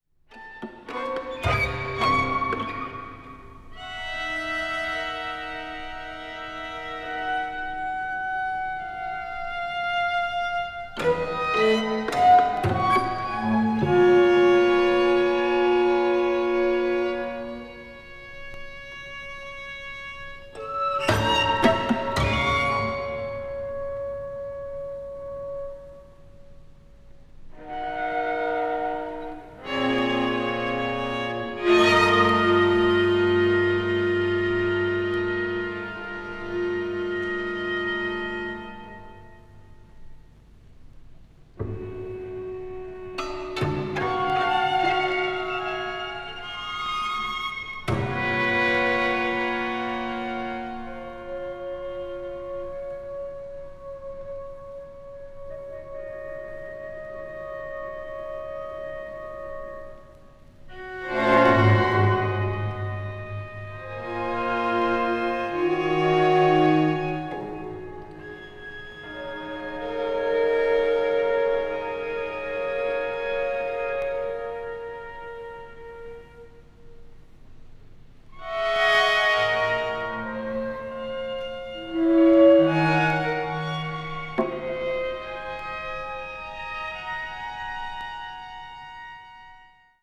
media : EX/EX(わずかにチリノイズが入る箇所あり)